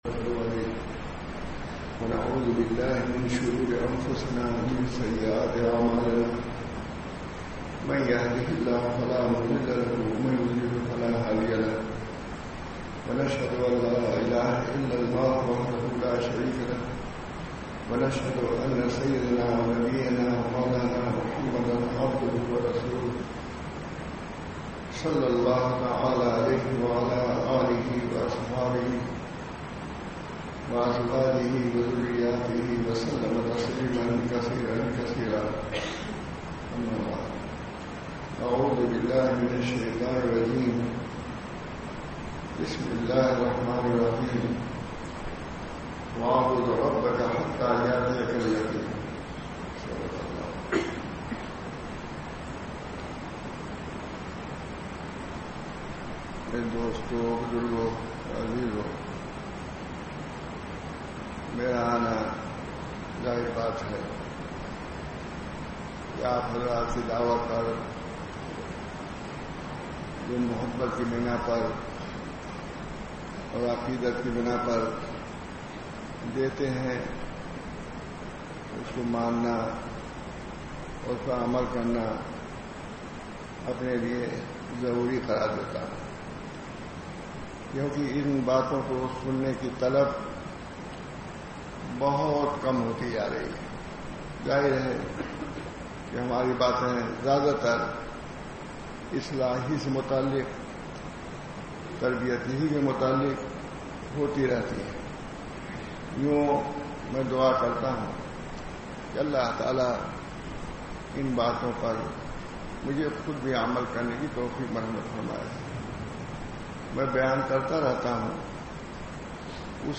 Bayan After Isha